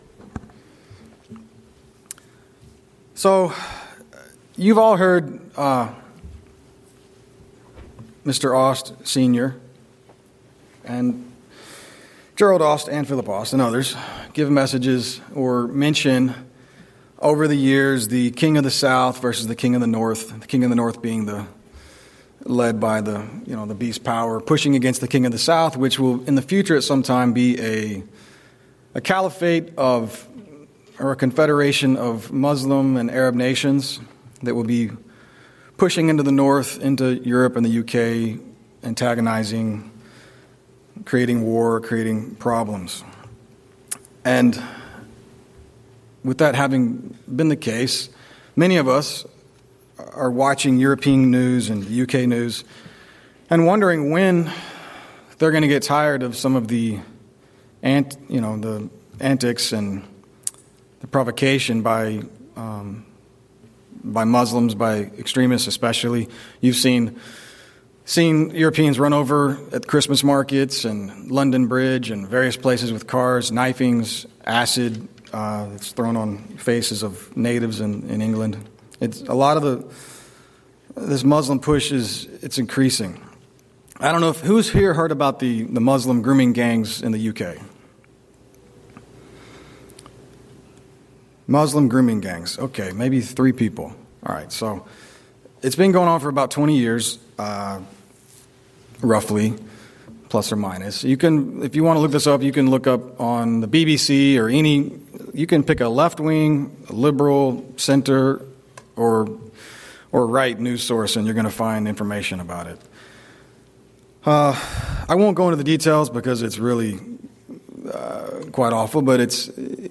Atlanta Georgia home congregation.
Sermons